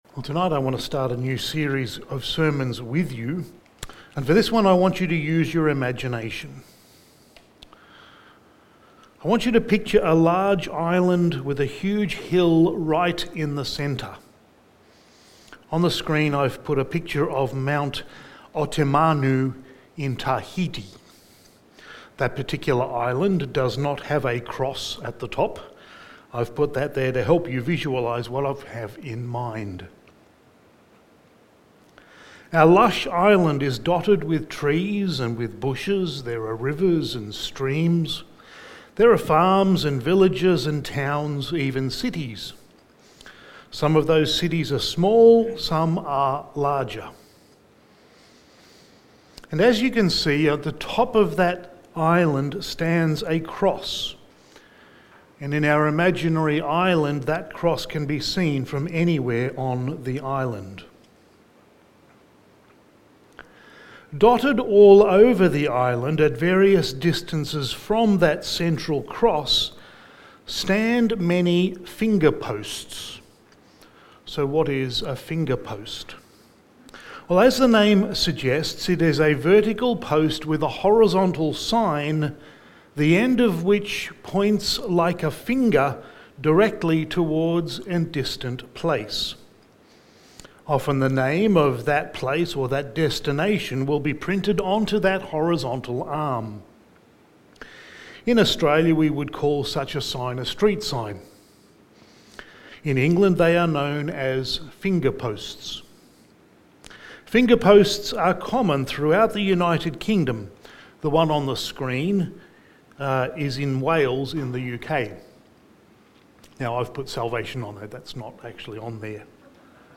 Sermon
Passage: Genesis 3:15 Service Type: Sunday Evening Sermon